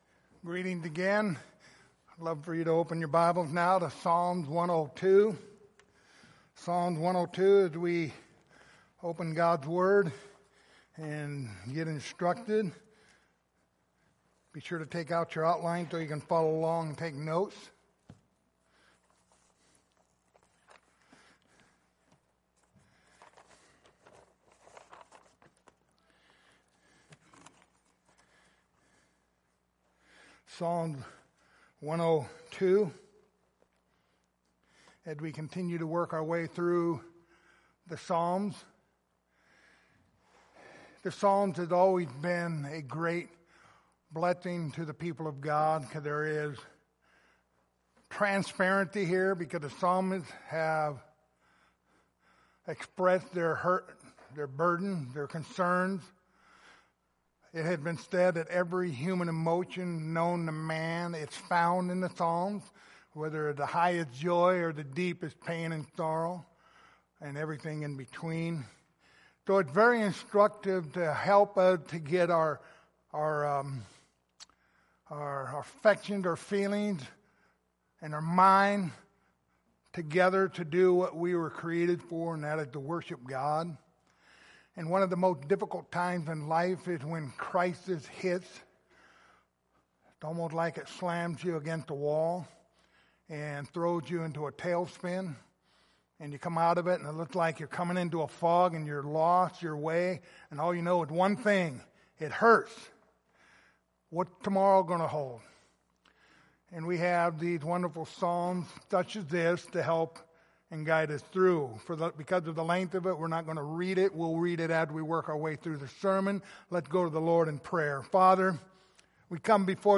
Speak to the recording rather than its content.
Passage: Psalms 102:1-28 Service Type: Sunday Morning